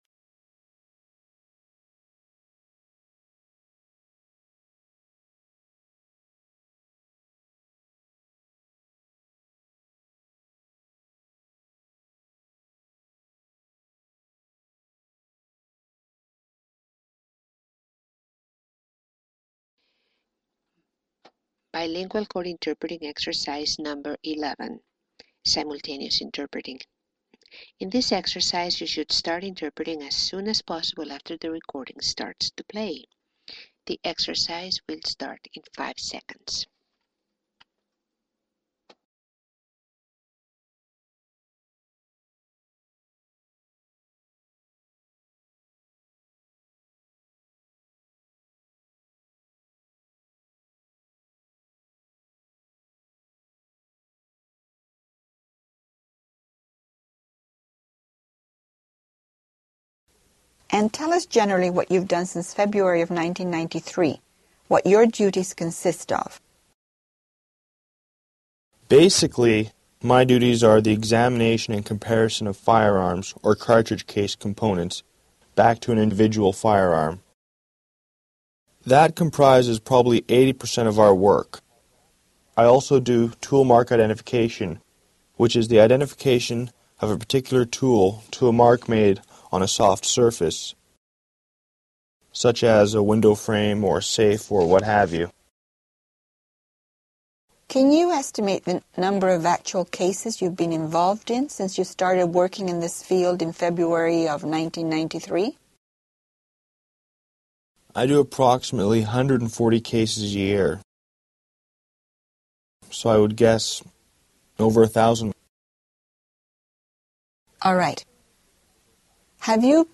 Ex. 11 Simultaneous Interpreting Dialogue BCIT recording